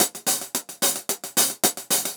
Index of /musicradar/ultimate-hihat-samples/110bpm
UHH_AcoustiHatC_110-04.wav